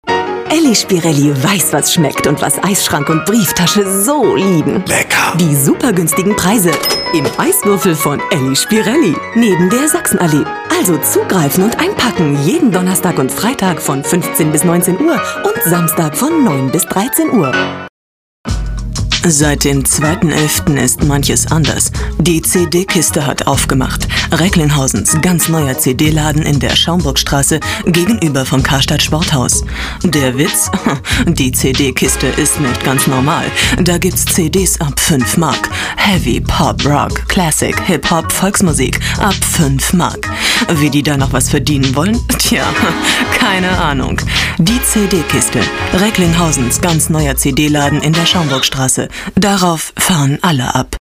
Profi-Sprecherin deutsch, Werbesprecherin.
Kein Dialekt
Sprechprobe: eLearning (Muttersprache):
female voice over artist german.